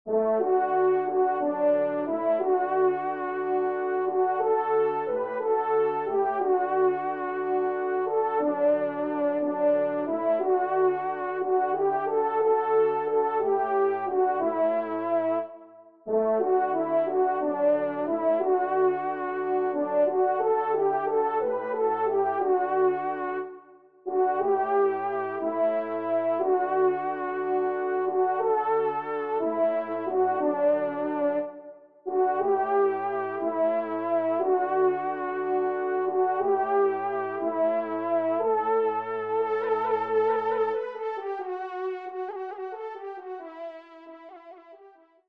Pupitre 1° Trompe (en exergue)